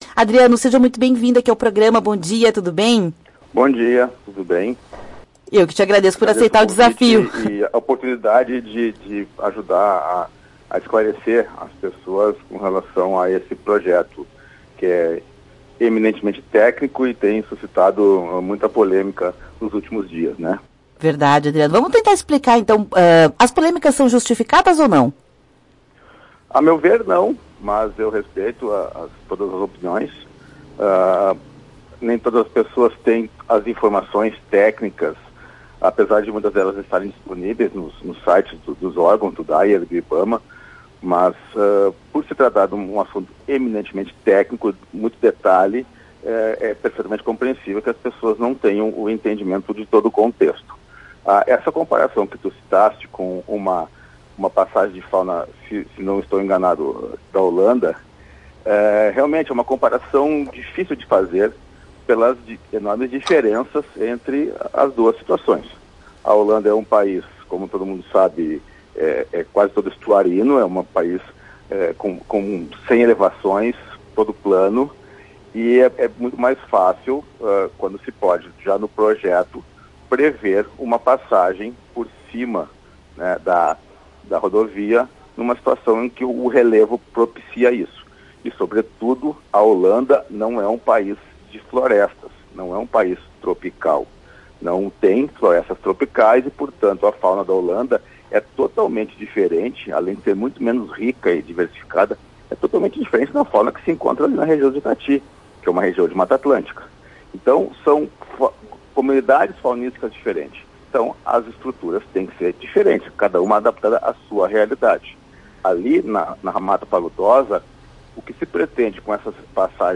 Biólogo explica como será o funcionamento das estruturas aéreas para passagem de anfíbios na Rota do Sol